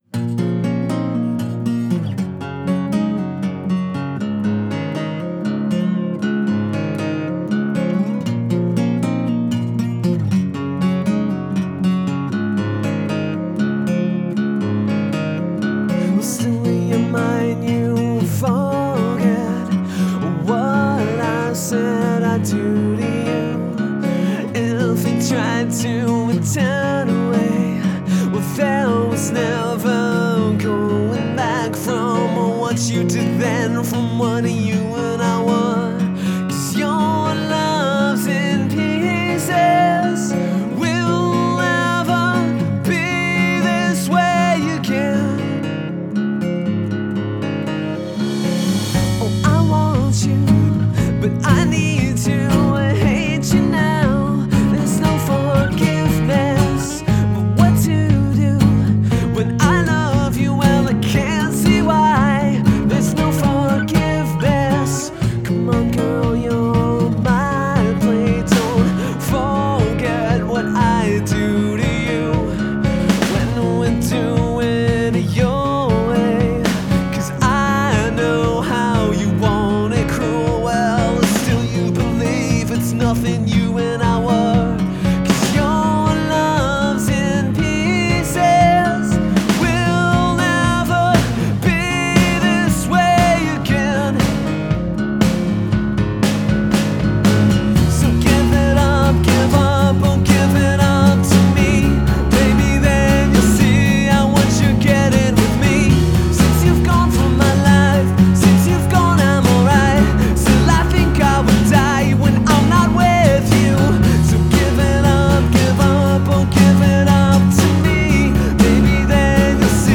• Genre: Acoustic / Alternative / Indie